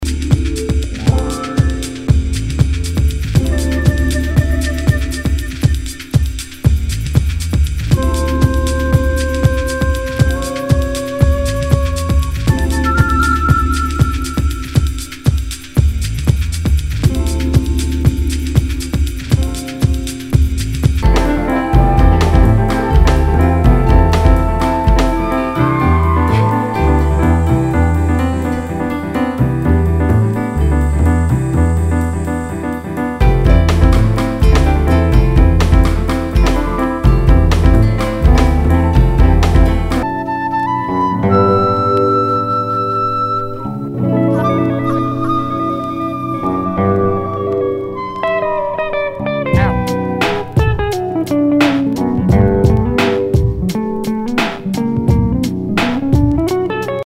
Nu- Jazz/BREAK BEATS
Future Jazz / ダウンテンポ！